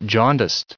1634_jaundiced.ogg